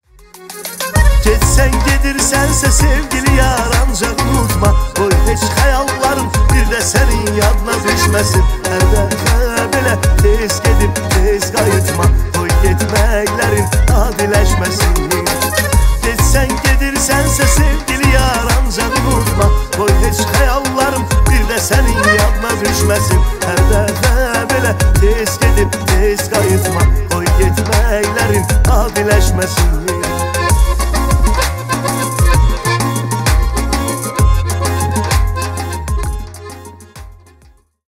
• Качество: 320 kbps, Stereo
Азербайджанские